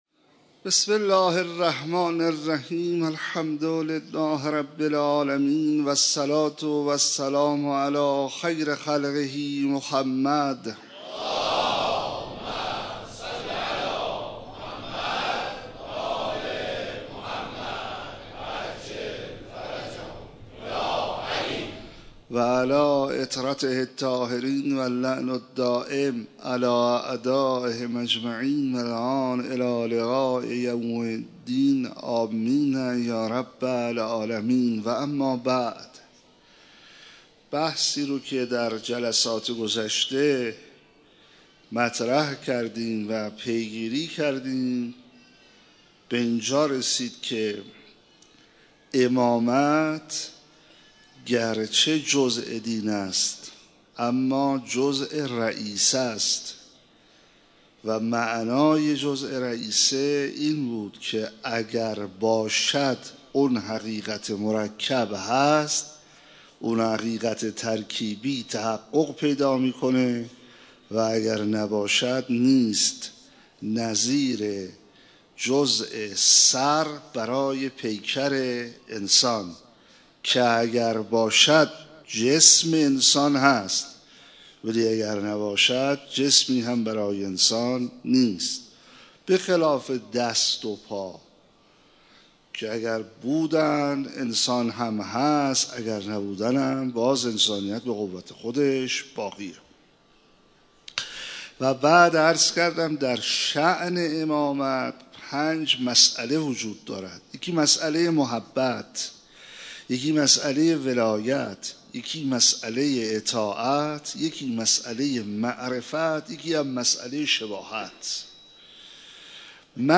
مراسم عزاداری شب پنجم محرم
در مسجد امیر (ع) برگزار شد.